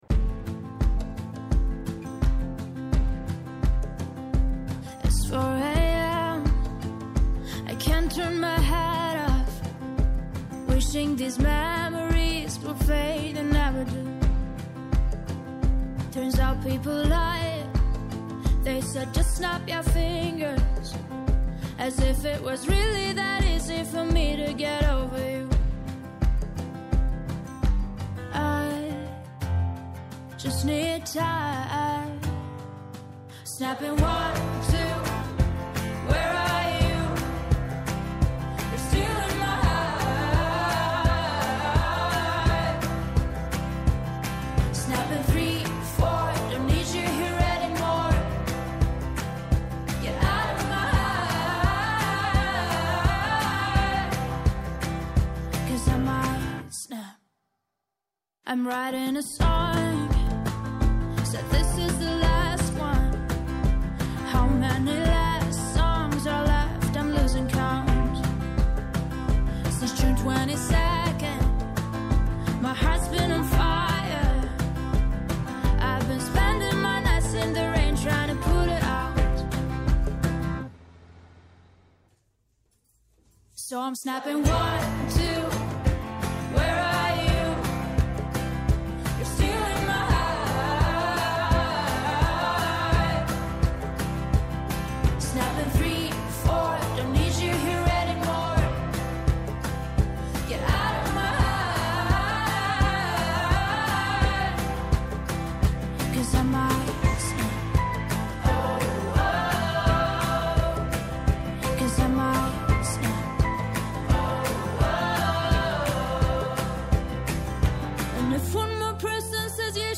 στο Πρώτο Πρόγραμμα της Ελληνικής Ραδιοφωνίας